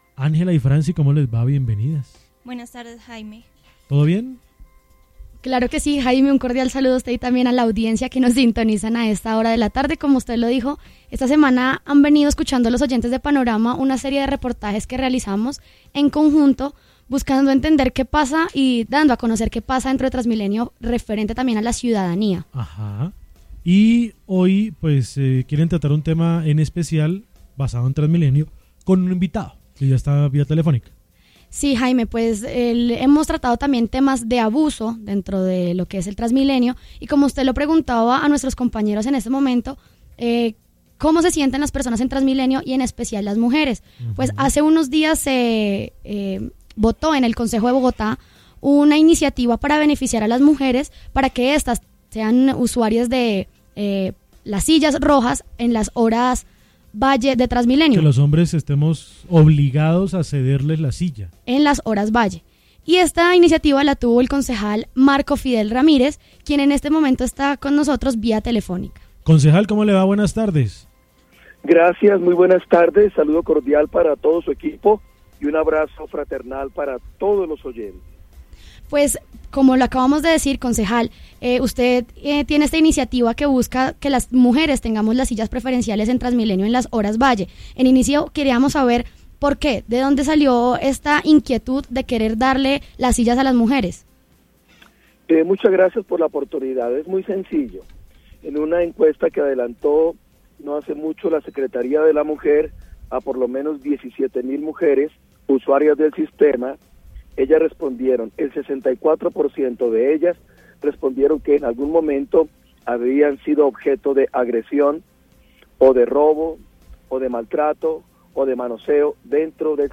En UNIMINUTO Radio estuvo el concejal Marco Fidel Ramírez hablando sobre su propuesta que los hombres deben ceder las sillas rojas del Transmilenio a las mujeres durante las horas pico.